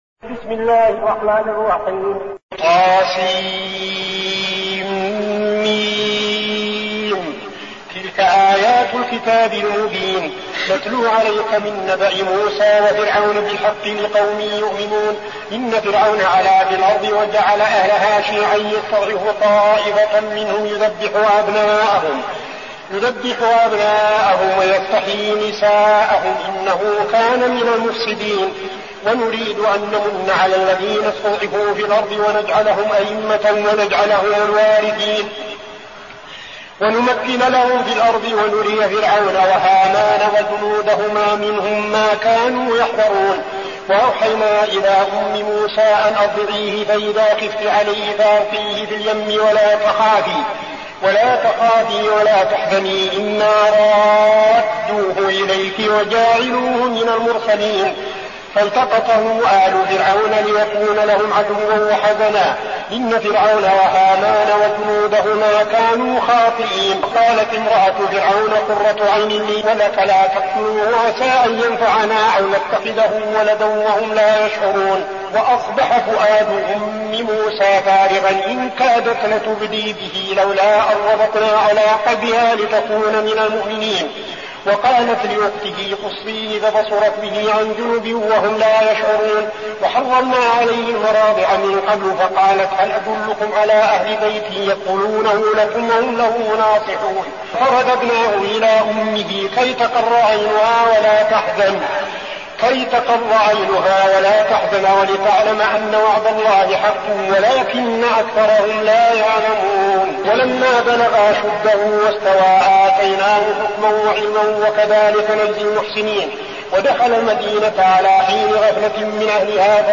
المكان: المسجد النبوي الشيخ: فضيلة الشيخ عبدالعزيز بن صالح فضيلة الشيخ عبدالعزيز بن صالح القصص